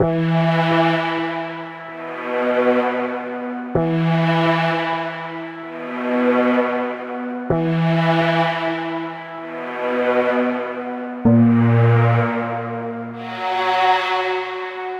不気味な部屋のようなホラーっぽい印象に仕上げました！
BPM：128 キー：Em ジャンル：ゆったり 楽器：ストリングス